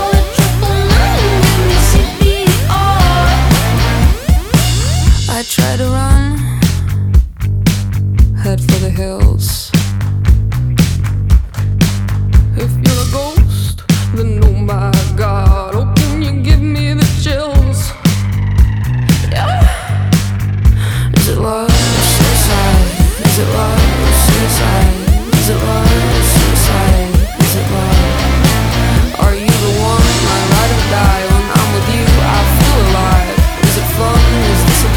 Жанр: Альтернатива Длительность